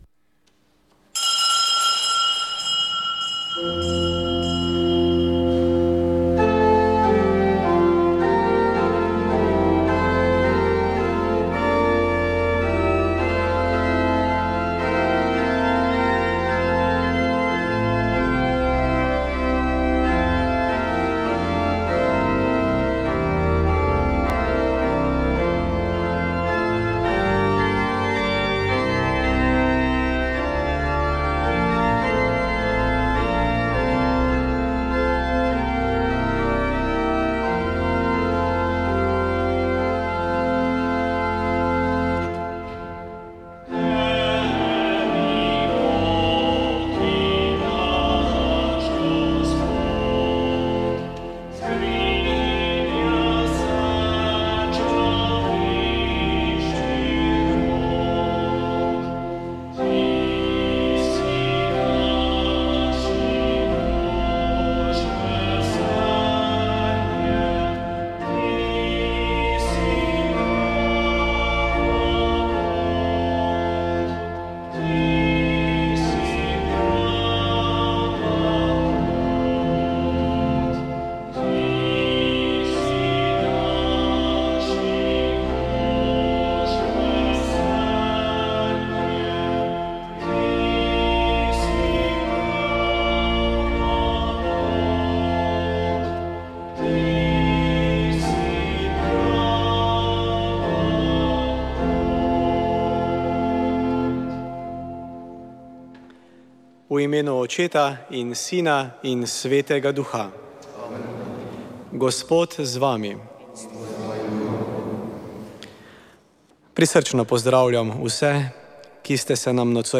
Sveta maša
Sv. maša iz bazilike Marije Pomagaj na Brezjah 9. 5.